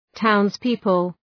Προφορά
{‘taʋnz,pıpəl}